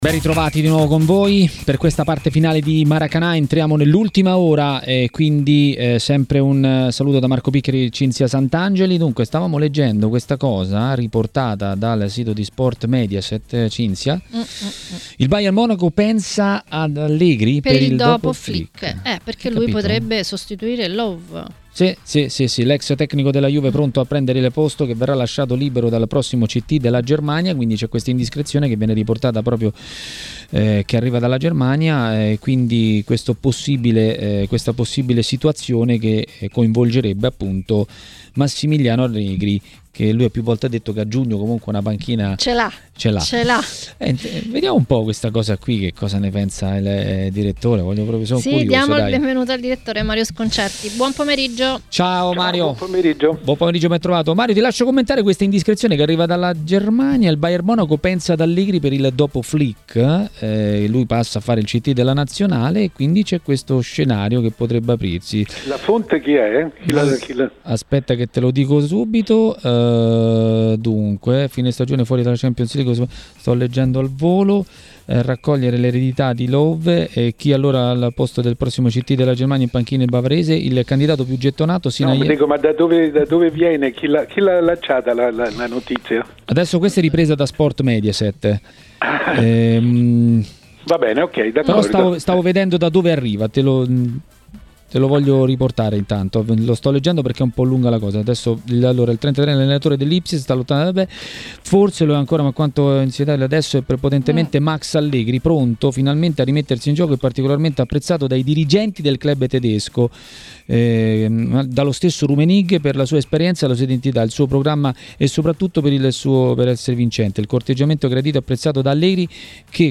Il direttore Mario Sconcerti a TMW Radio, durante Maracanà, ha parlato di tanti temi tra cui il futuro della panchina del Napoli